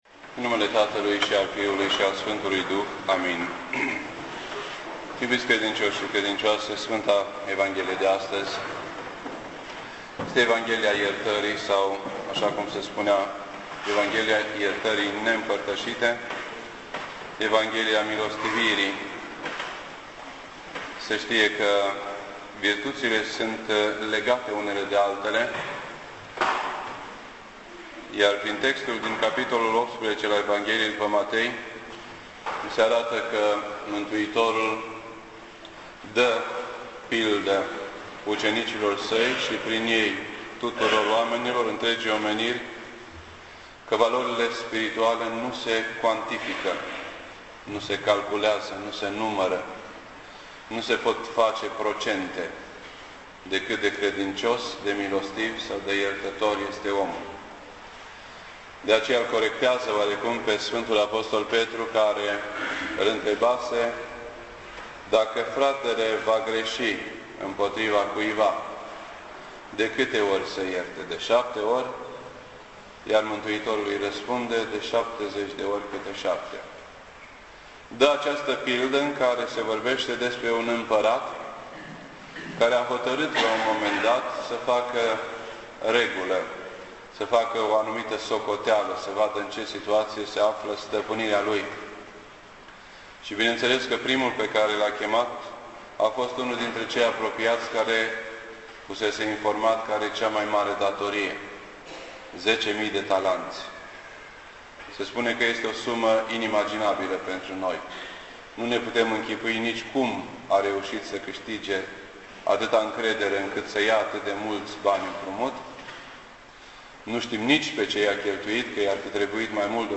This entry was posted on Sunday, August 23rd, 2009 at 6:39 PM and is filed under Predici ortodoxe in format audio.